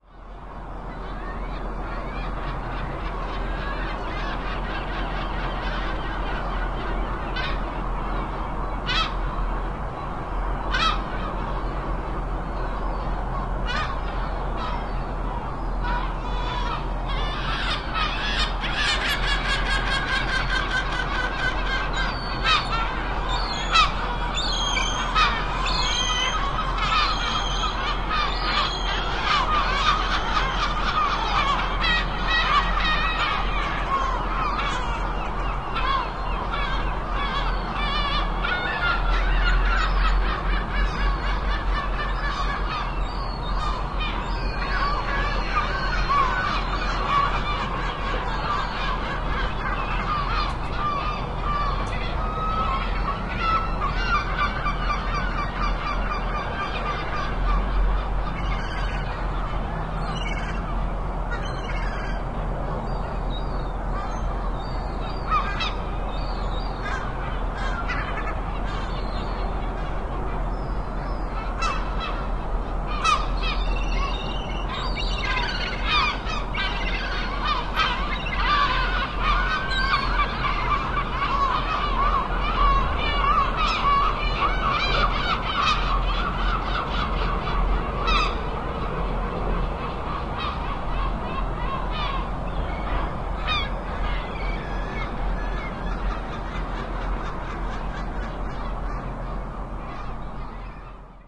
描述：各种鸟，主要是海鸥。用索尼M10在我的公寓窗户上录制。
Tag: 城市 海鸥 乌鸦